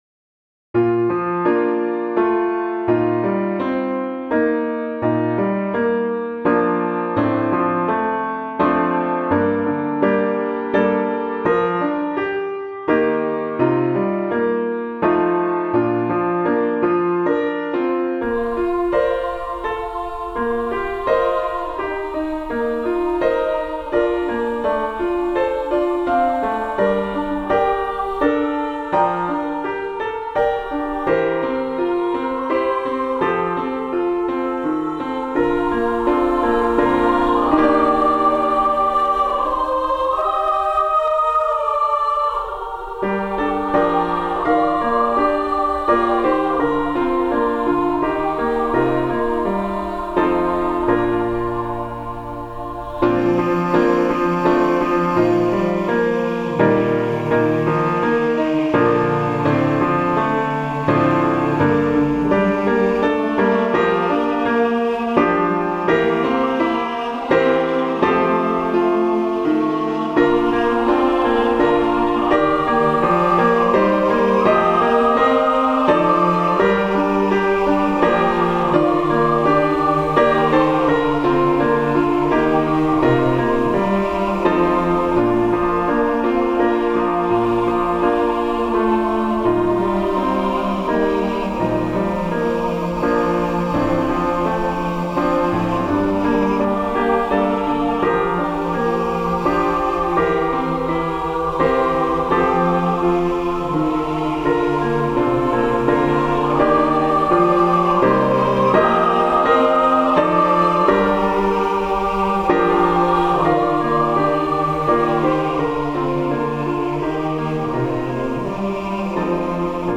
Arranged for our small church choir to demonstrate the hymn, then congregation joins in last verse.
Voicing/Instrumentation: SAB We also have other 1 arrangement of " To Love like Thee ".
Choir with Congregation together in certain spots